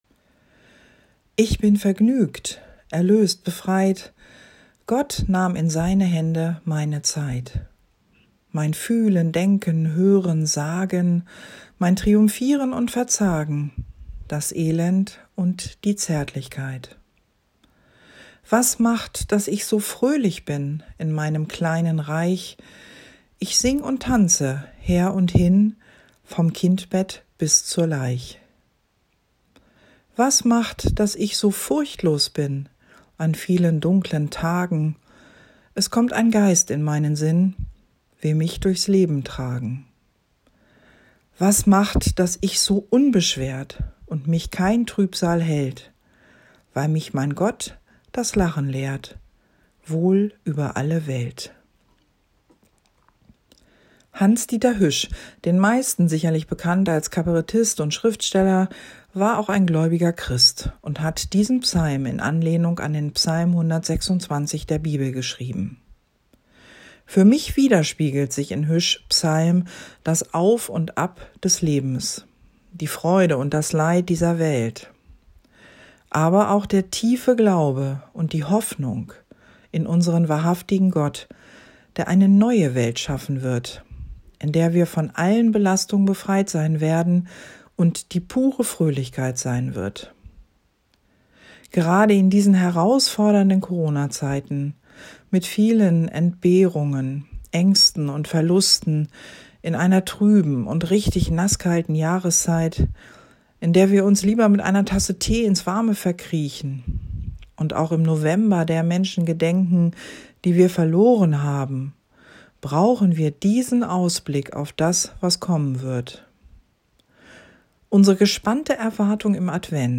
Ich bin vergnügt – Kurzandacht